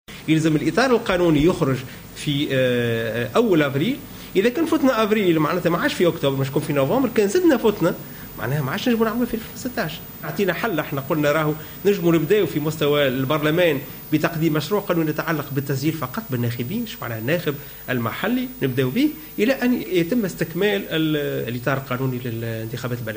وأضاف في تصريح للوطنية الأولى أنه من الممكن إجراء الانتخابات البلدية إذا تم إصدار النصوص القانونية خلال شهر افريل القادم على أن يتم إجراء الانتخابات خلال شهر أكتوبر.